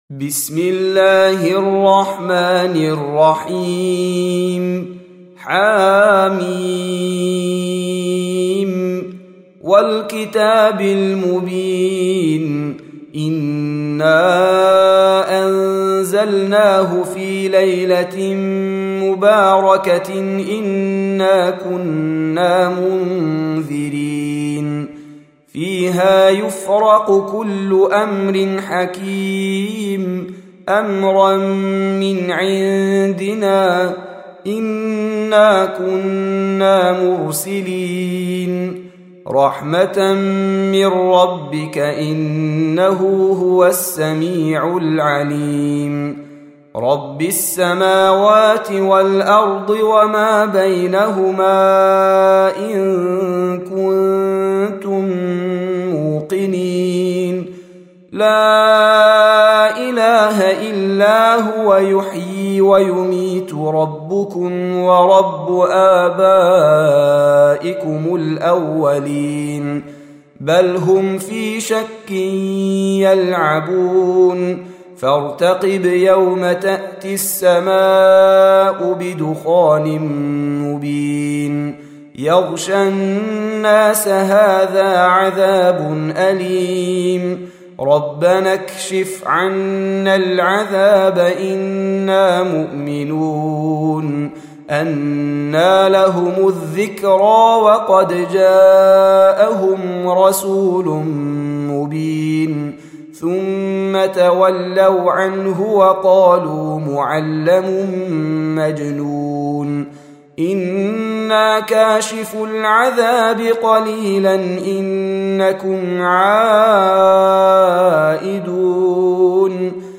Surah Repeating تكرار السورة Download Surah حمّل السورة Reciting Murattalah Audio for 44. Surah Ad-Dukh�n سورة الدّخان N.B *Surah Includes Al-Basmalah Reciters Sequents تتابع التلاوات Reciters Repeats تكرار التلاوات